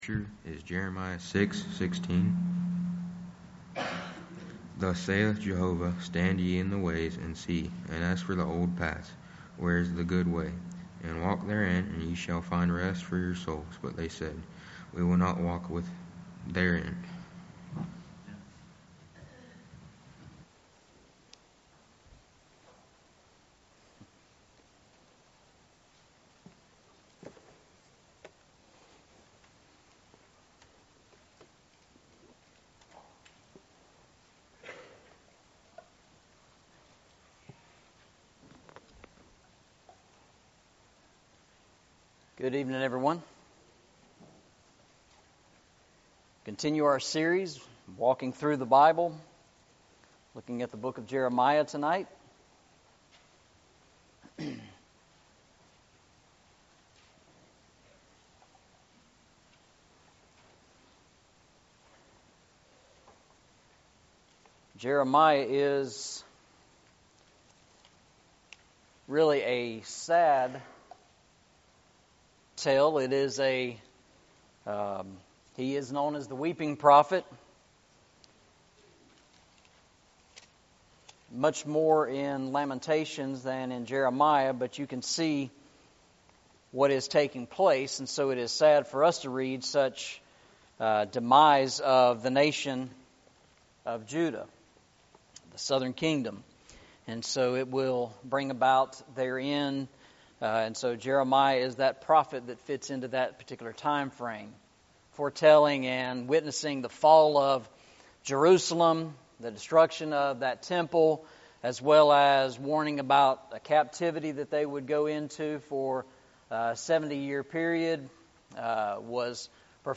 Eastside Sermons Passage: Jeremiah 6:16 Service Type: Sunday Evening « What if Christ Had Not Come Into the World?